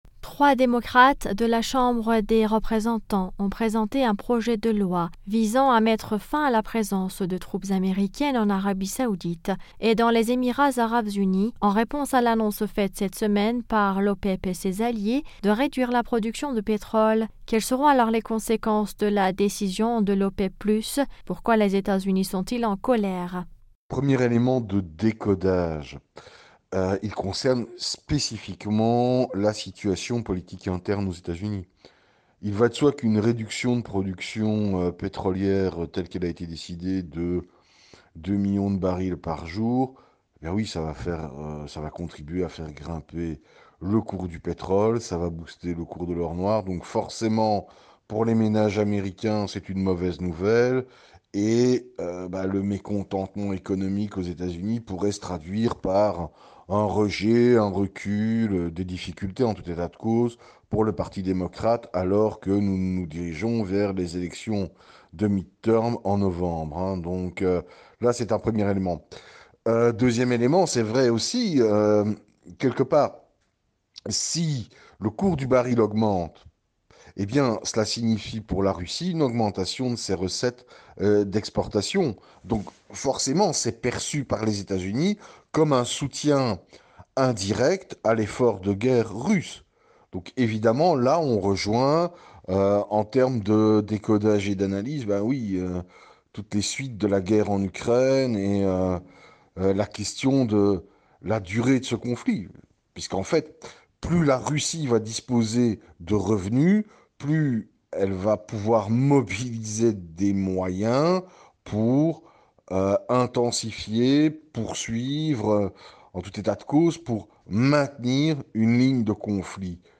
économiste nous en dit plus.